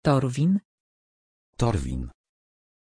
Pronunciation of Torwin
pronunciation-torwin-pl.mp3